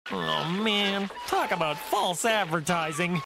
oh man talk about false advertising Meme Sound Effect
This sound is perfect for adding humor, surprise, or dramatic timing to your content.